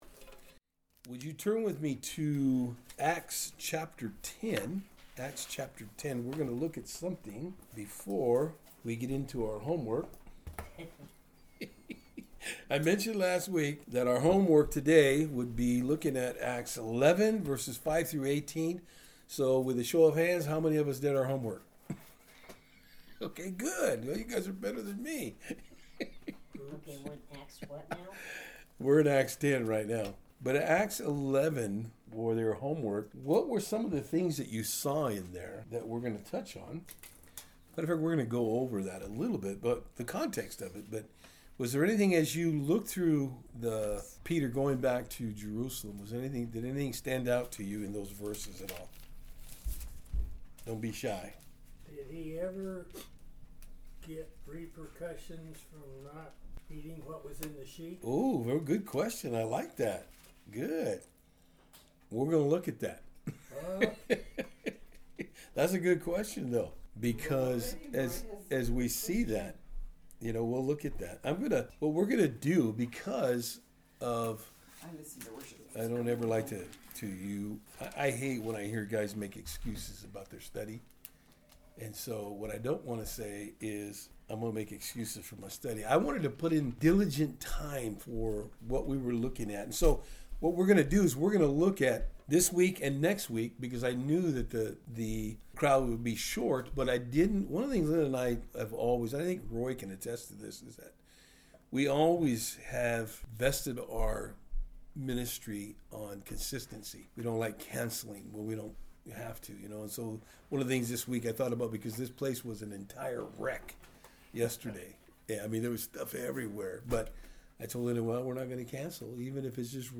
Service Type: Thursday Afternoon